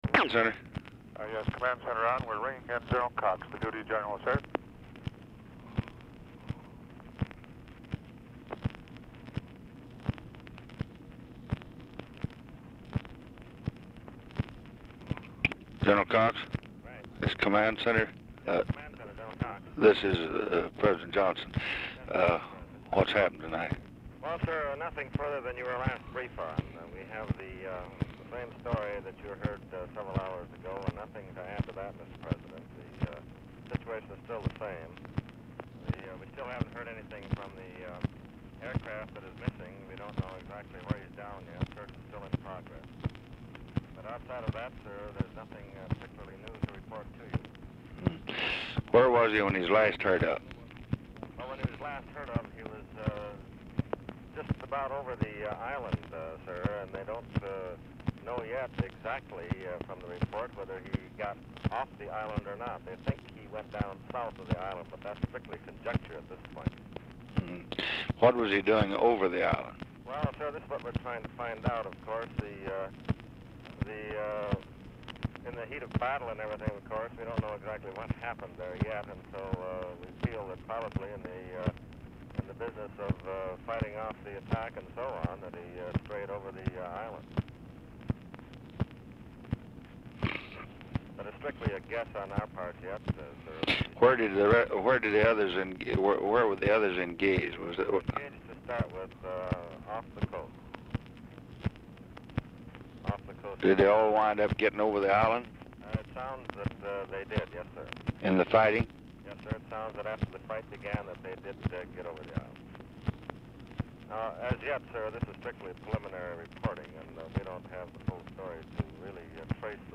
Format Dictation belt
Location Of Speaker 1 Mansion, White House, Washington, DC
Specific Item Type Telephone conversation Subject Communist Countries Defense East Asia And The Pacific Vietnam